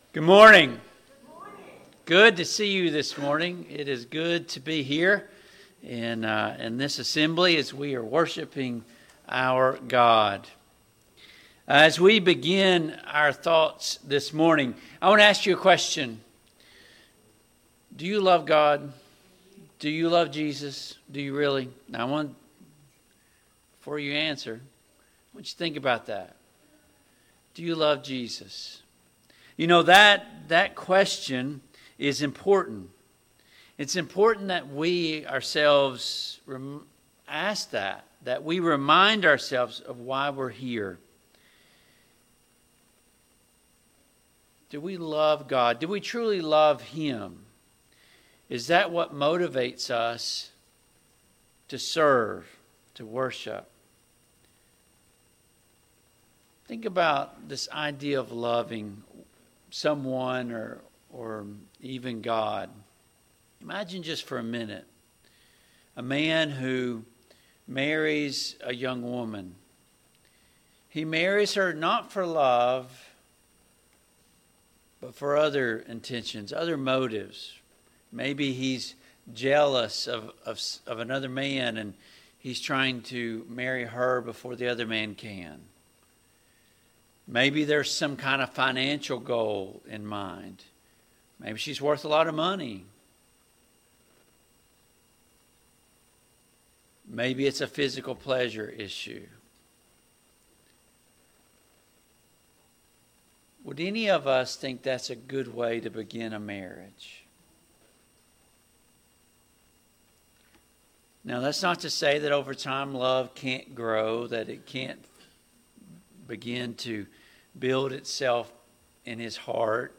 Service Type: AM Worship Download Files Notes Topics: Dedication to Jesus , Love « 4.